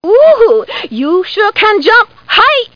jump2.mp3